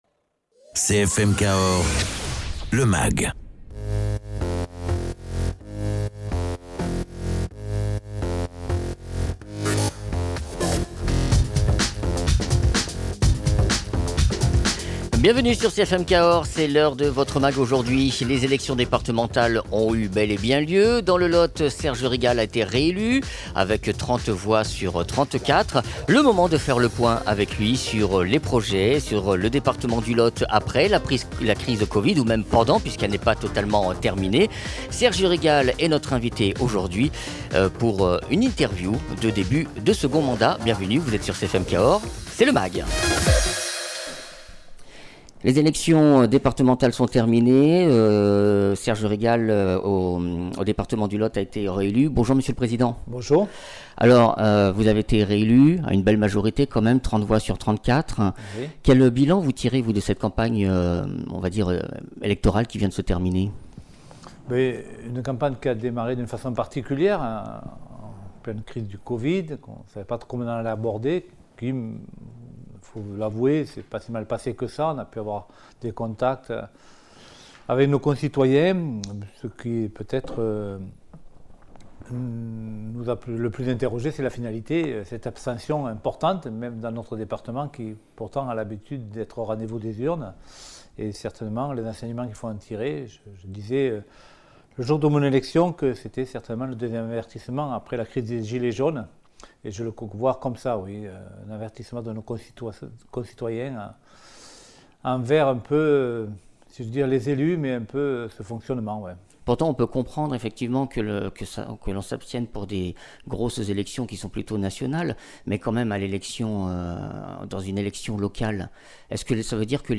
Invité(s) : Serge Rigal, Président du conseil départementale du Lot